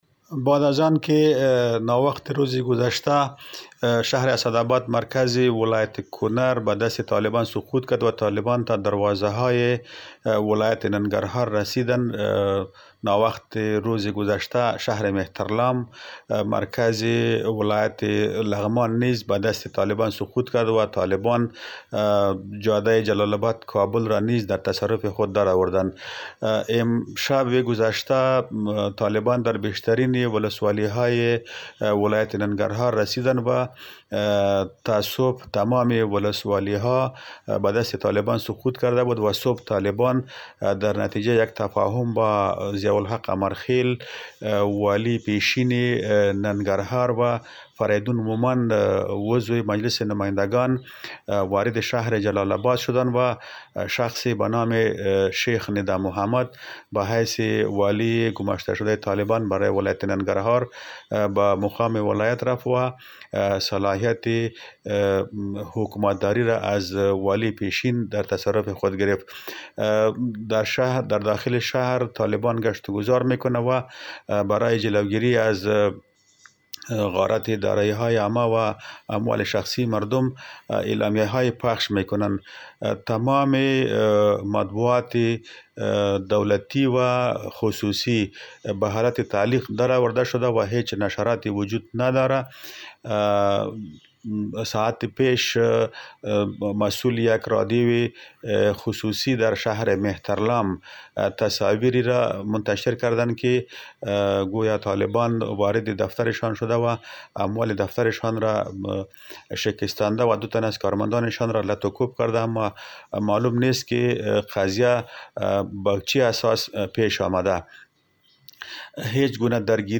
خبر رادیو